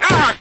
Worms speechbanks
Ooff1.wav